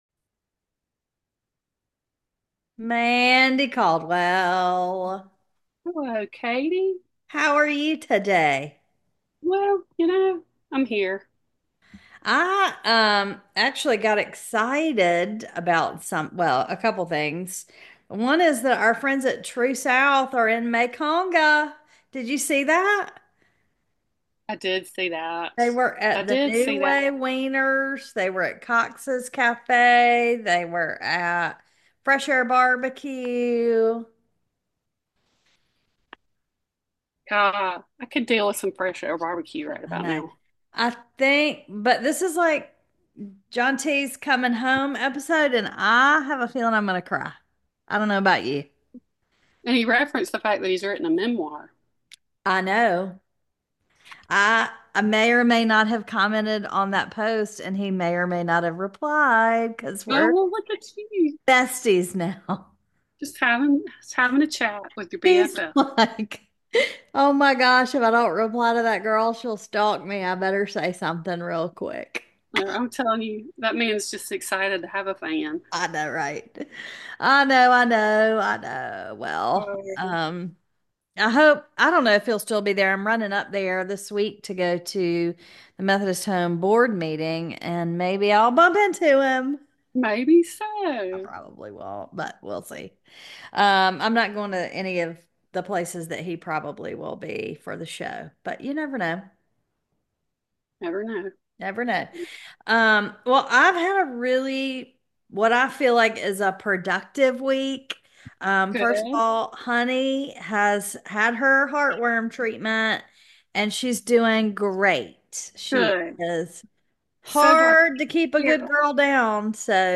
Despite a few technical glitches, Episode 86 is in the bag! We celebrate all things snot and finished coursework as well as talk baseball and the NFL draft. We may throw a little Pope draft in there for good measure.